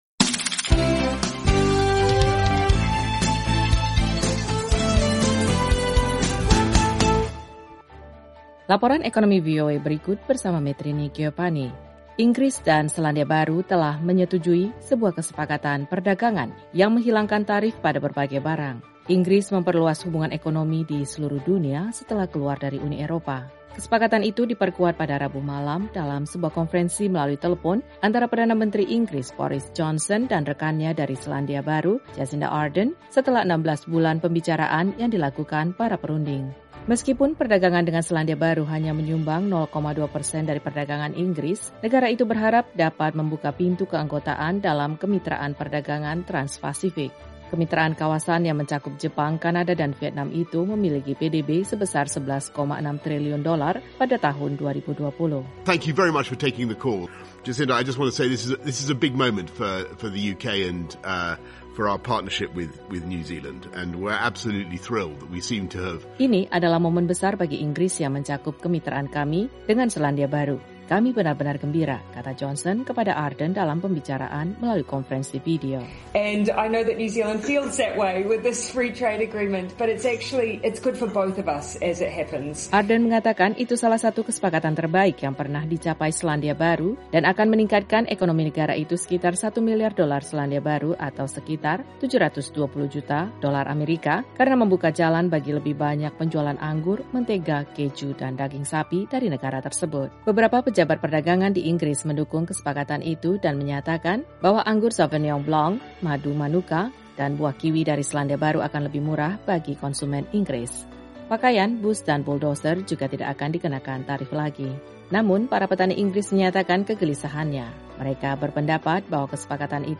Laporan Ekonomi VOA mengenai Borison Johnson dan Jacinda Arden menyetujui kesepakatan perdagangan Inggris-New Zealand.